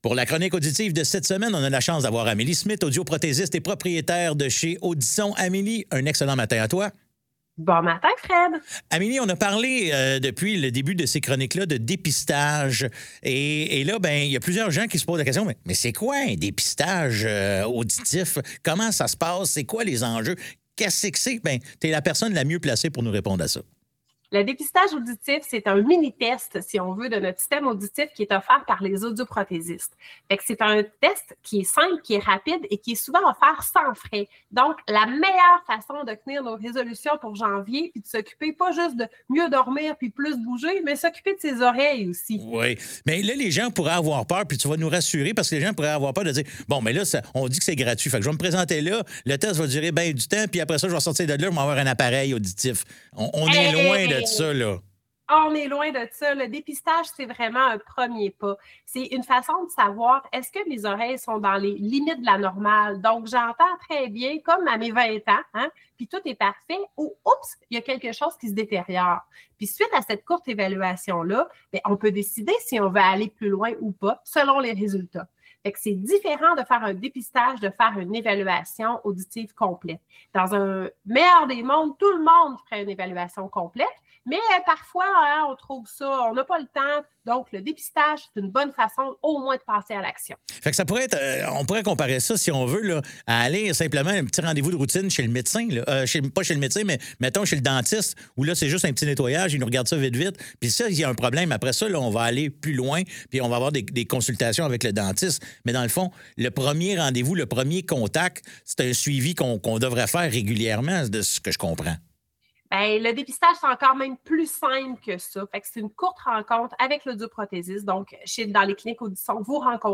Chroniques radio